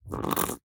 1.21.4 / assets / minecraft / sounds / mob / fox / aggro2.ogg